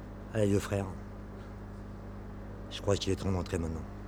groupe_electro.wav